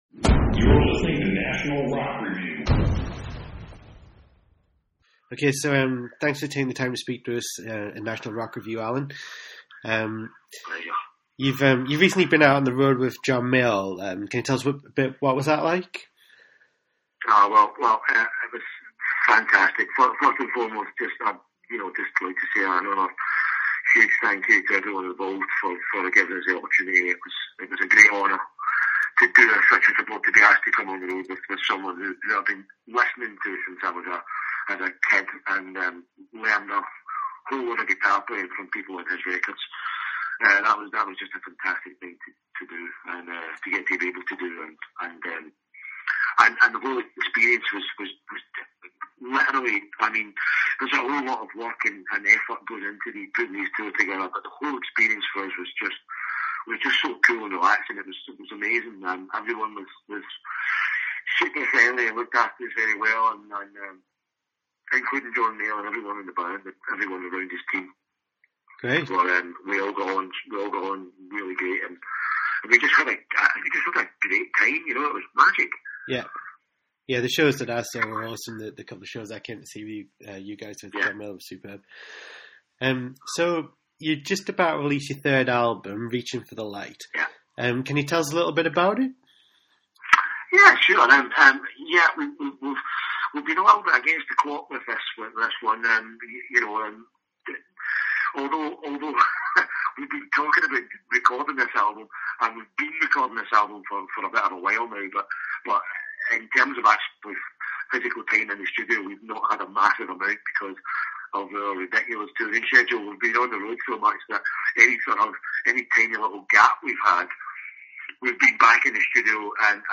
King King Interview